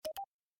click2.mp3